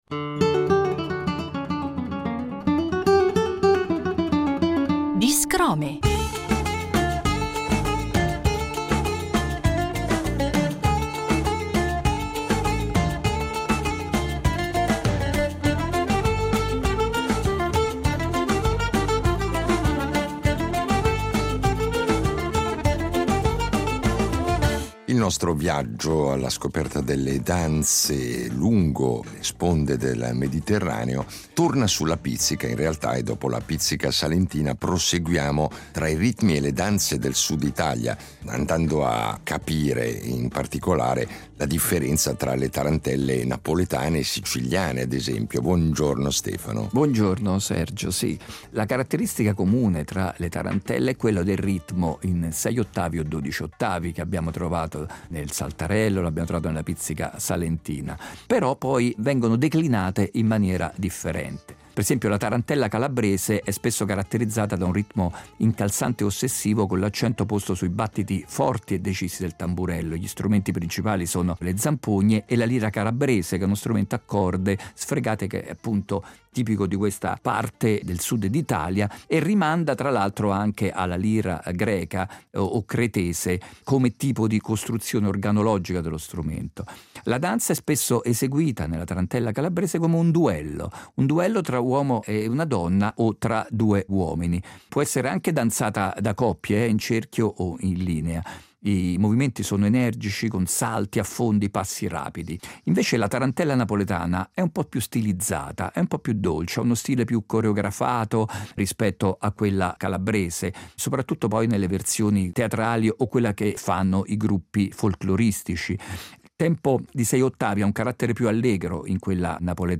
Italia-Tarantella siciliana